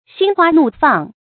注音：ㄒㄧㄣ ㄏㄨㄚ ㄋㄨˋ ㄈㄤˋ
心花怒放的讀法